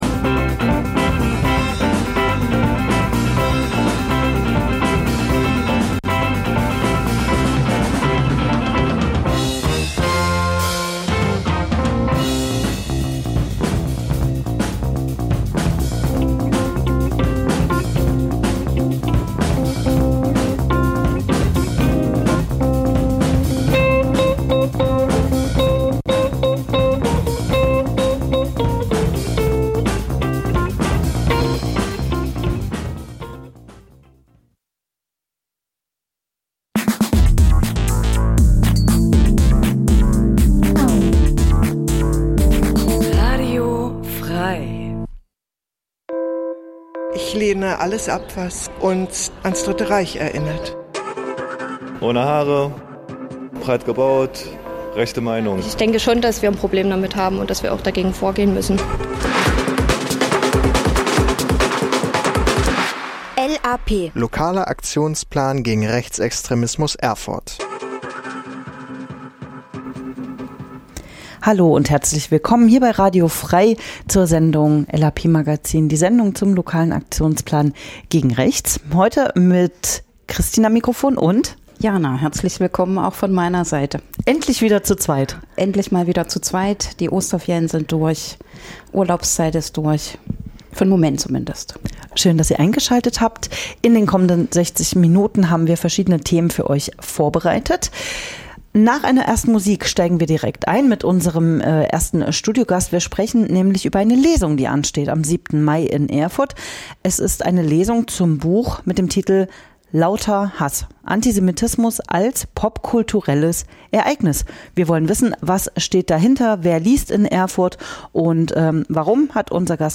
In der Sendung h�rt ihr verschiedene Beitr�ge rund um Demokratie und gegen Rechts, z. B. - Interviews zu aktuellen Themen - Veranstaltungshinweise - Musikrubrik "Coole Cover" --- Die Sendung l�uft jeden zweiten Mittwoch 11-12 Uhr (Wiederholung: Donnerstag 20 Uhr) und informiert �ber Themen, Projekte und Termine gegen Rechts in Erfurt und Umgebung.